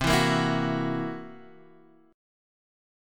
C7b5 chord